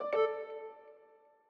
Longhorn Ten Beta - Message Nudge.wav